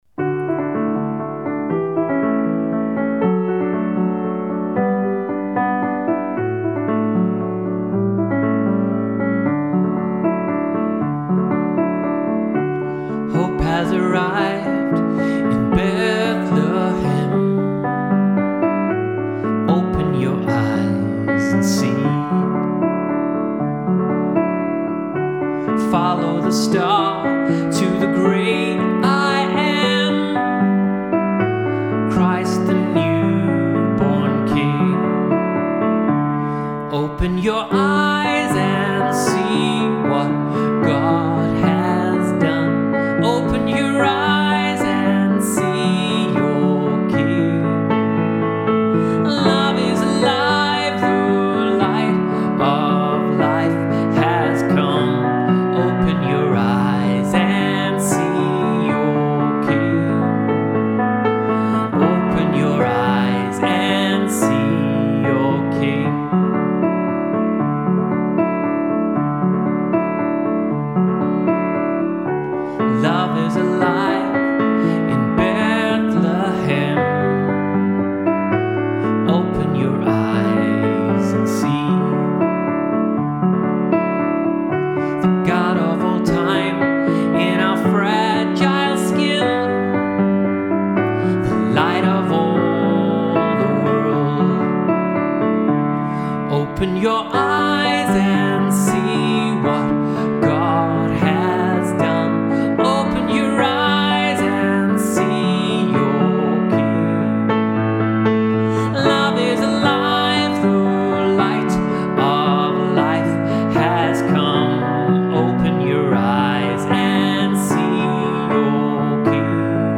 So I wrote this song, which is more a traditional christmas carol, must more upbeat, and more suitable to family worship in a church setting. I first named this song "The Dancing Song", as it's written in 3/4 (a waltz), and the image I had in my mind was of creation dancing and celebrating at Christs birth.